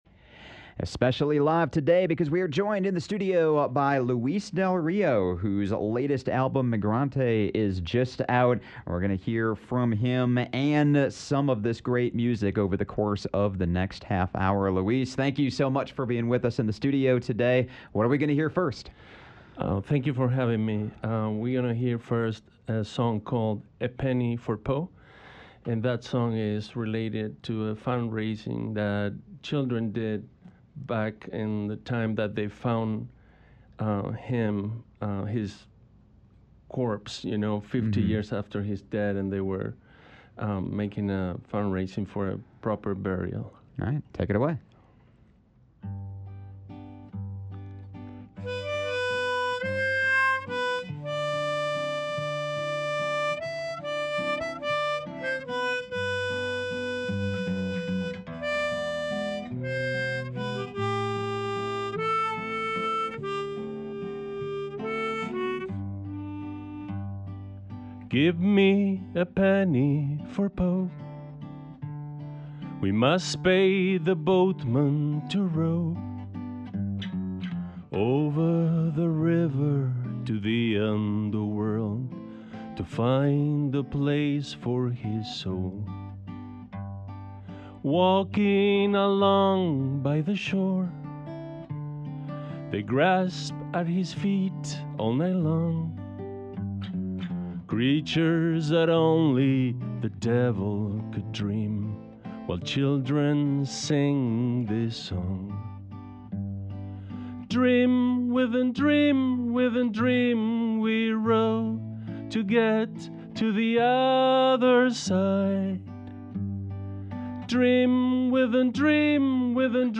playing selections from his body of music